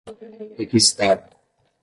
Pronunciado como (IPA) /ʁe.ki.ziˈta(ʁ)/